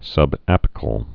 (sŭb-ăpĭ-kəl, -āpĭ-)